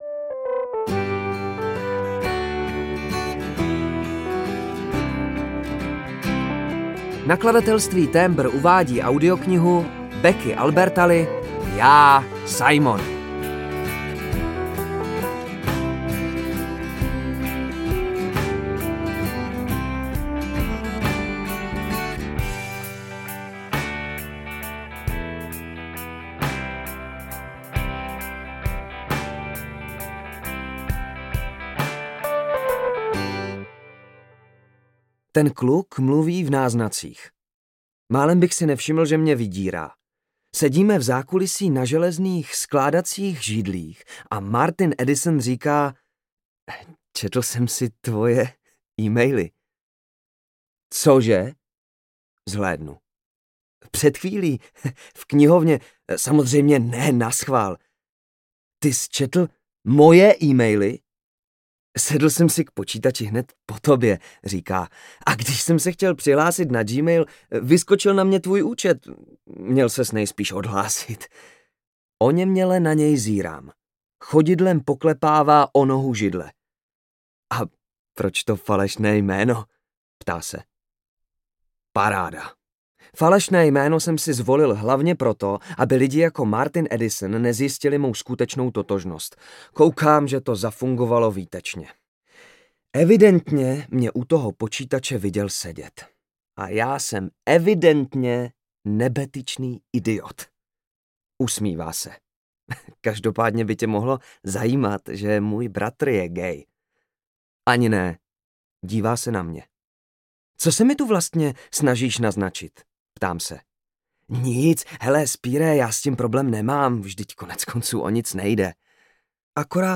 JÁ, SIMON audiokniha
Ukázka z knihy